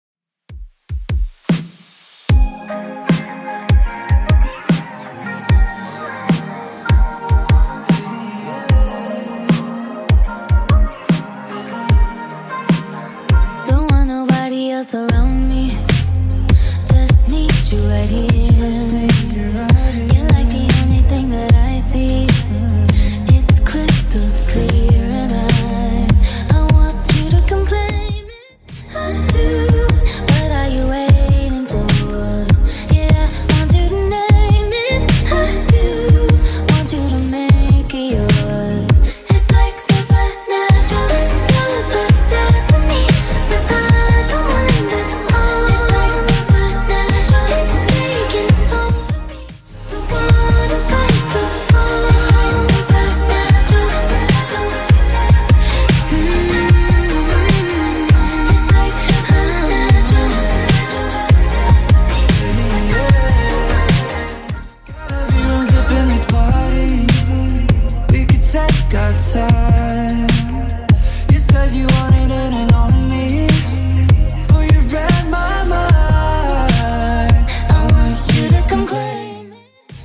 Genre: 80's
BPM: 122